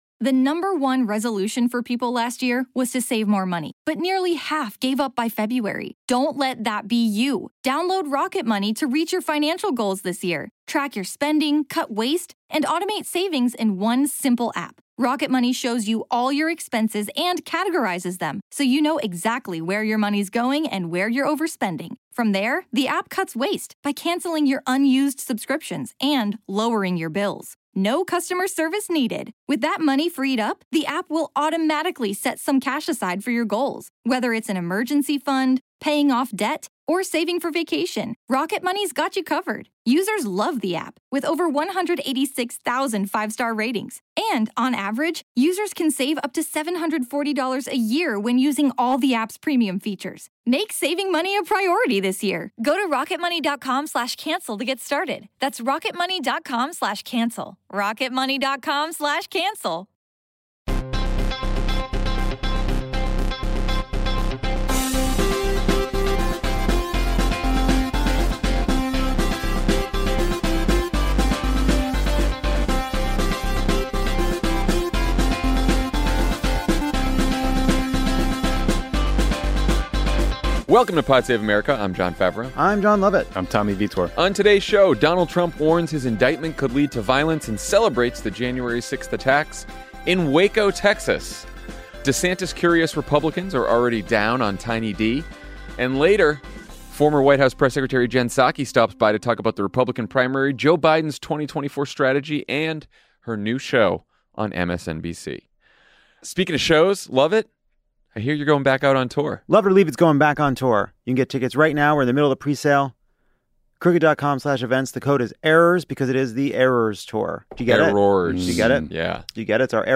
Donald Trump warns his indictment could cause violence and celebrates the Jan 6 attacks in Waco, TX. Desantis-curious Republicans are already down on Tiny D. And later Jen Psaki stops by to talk about the Republican primary, Joe Biden’s 2024 strategy and her new show on MSNBC.